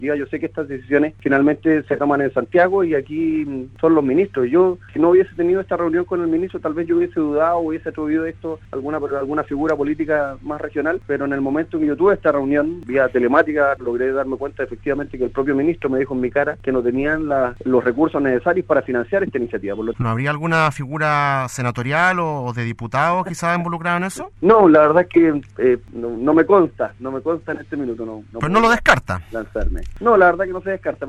En conversación con el programa “Primera Hora” de Radio Sago, el edil de la comuna, Sebastián Cruzat, recordó que hace más de dos años, el Ministerio de Vivienda bajó sorpresivamente el financiamiento de las que iban a ser las obras de reposición del parque La Toma.